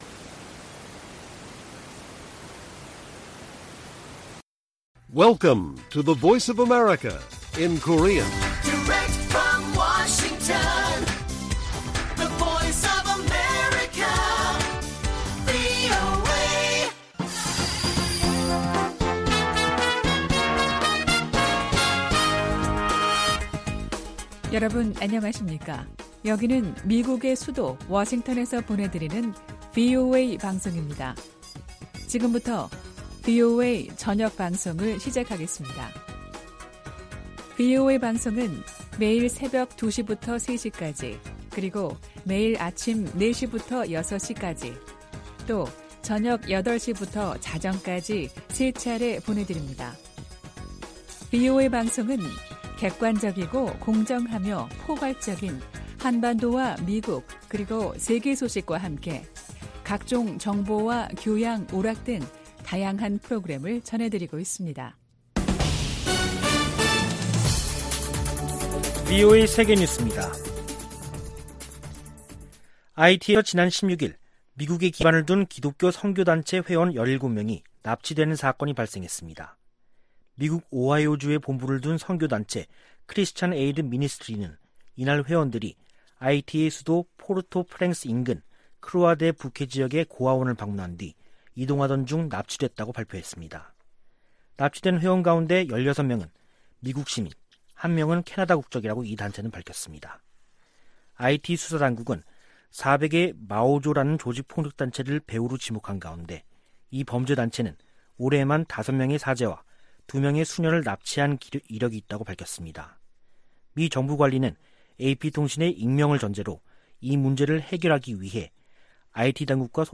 VOA 한국어 간판 뉴스 프로그램 '뉴스 투데이', 2021년 10월 18일 1부 방송입니다. 미군 정보당국이 새 보고서에서 북한이 장거리 미사일 시험 발사와 핵실험을 재개할 수 있다고 전망했습니다. 미 국무부는 한반도의 완전한 비핵화 목표를 진전시키기 위해 한국, 일본과 긴밀한 협력을 지속하고 있다고 밝혔습니다. 이인영 한국 통일부 장관은 보건방역 분야 대북 인도적 협력 방안에 대해 미-한 간 구체적인 진전이 있다고 밝혔습니다.